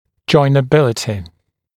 [ˌʤɔɪnə’bɪlətɪ][ˌджойнэ’билэти]способность металла быть припаянным или приваренным к другому металлу